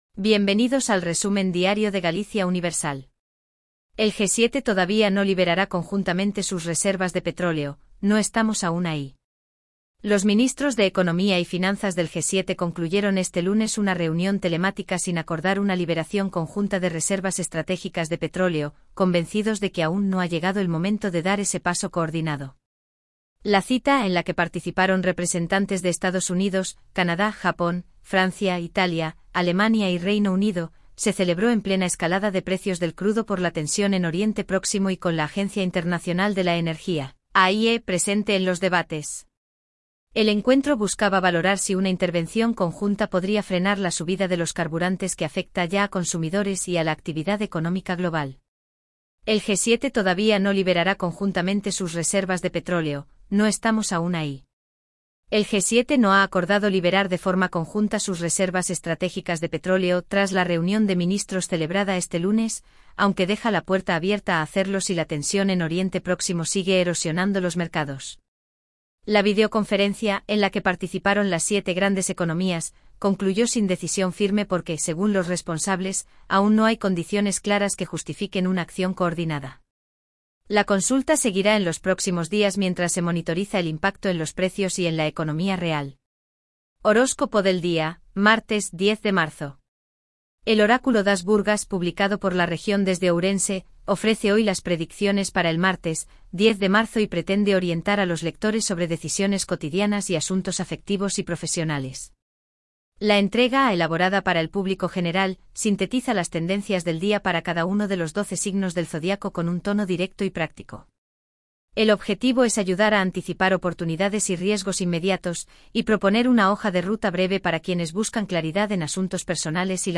Voz: Elvira · Generado automáticamente · 5 noticias